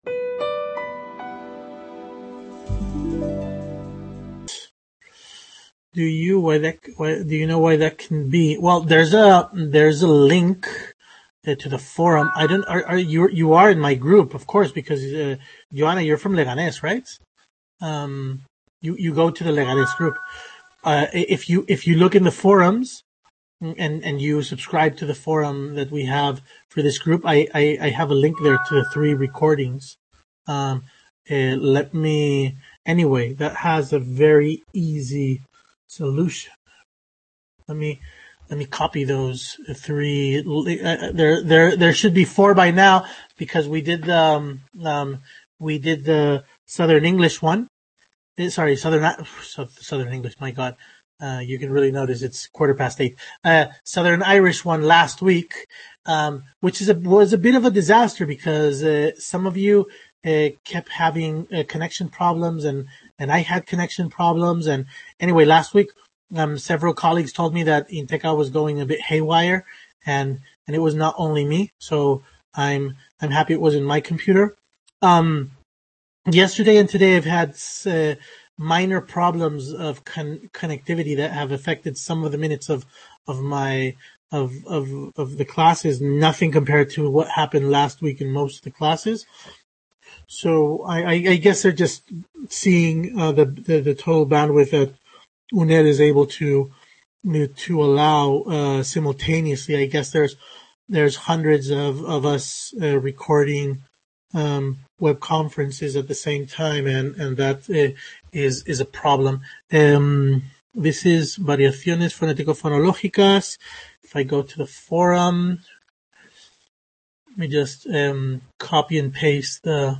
Webconference held on April 2nd, 2020